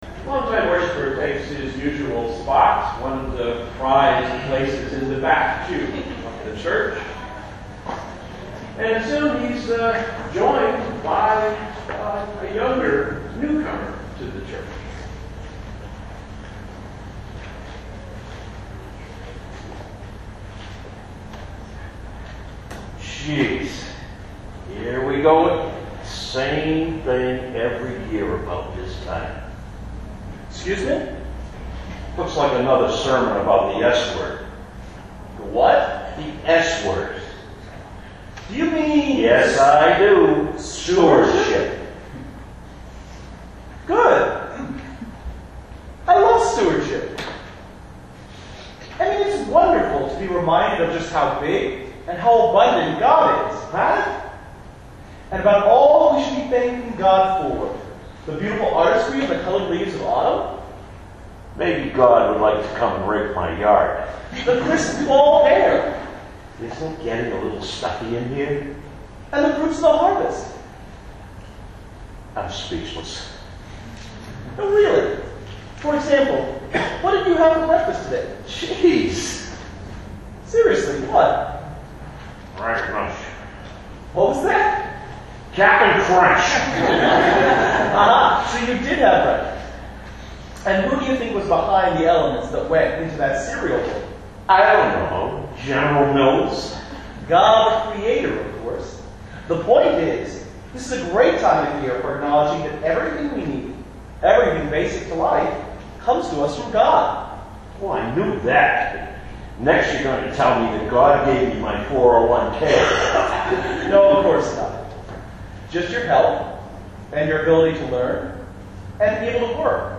The audio quality on this recording isn’t perfect, but the skit is just as funny as I remember.